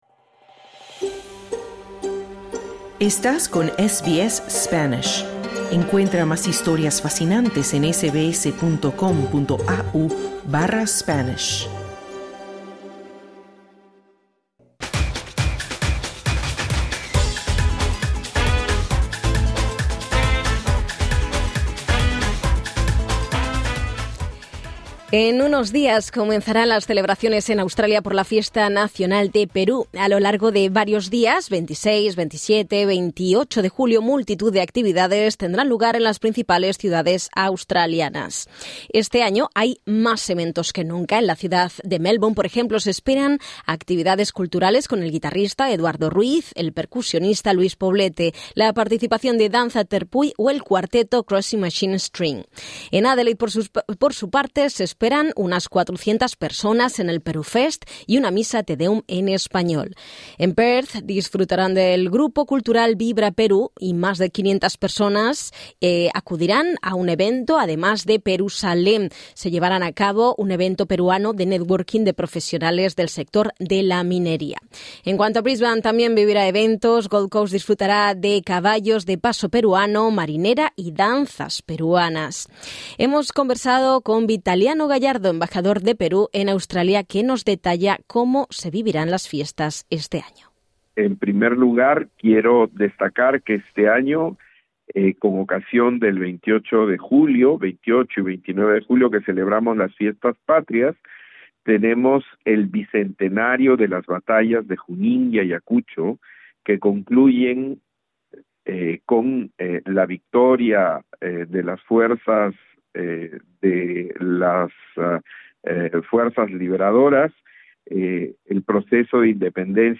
SBS Spanish ha conversado con Vitaliano Gallardo, embajador de Perú en Australia, que detalla cómo se vivirán las fiestas este año en Australia